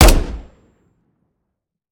weap_delta_fire_plr_01.ogg